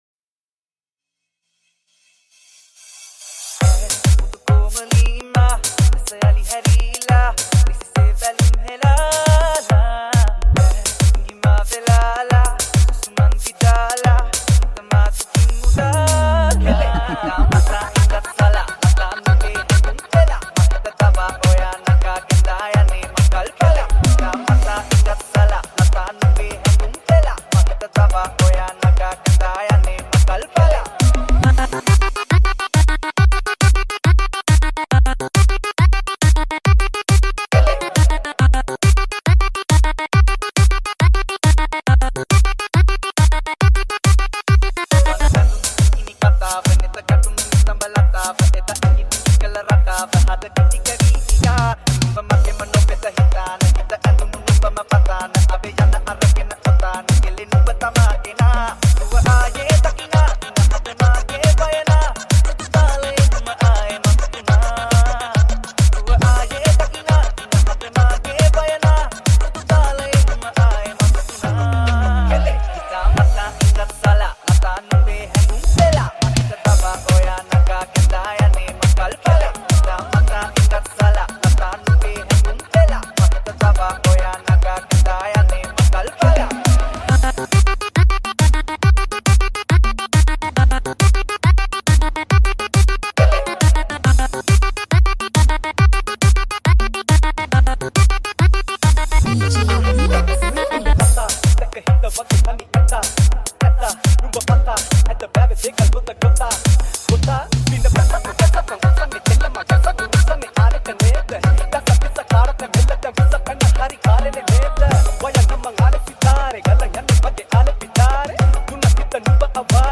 High quality Sri Lankan remix MP3 (2.5).